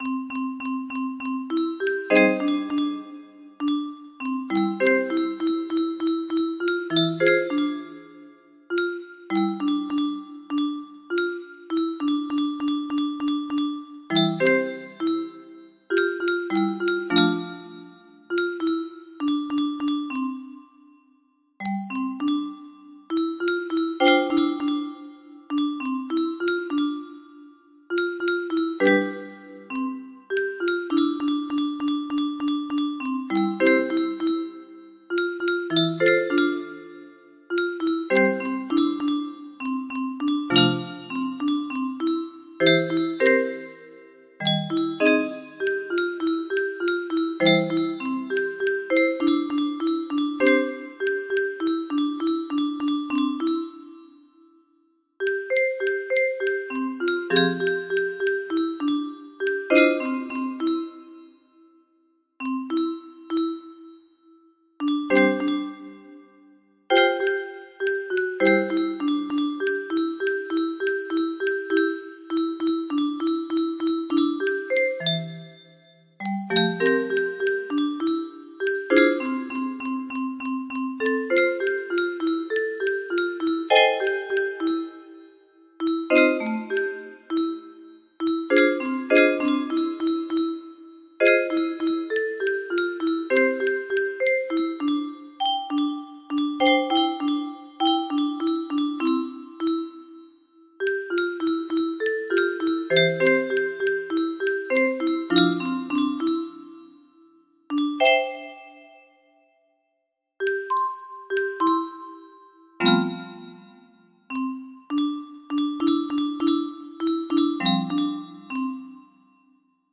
Unknown Artist - Untitled music box melody
Full range 60
Imported from MIDI from imported midi file (1).mid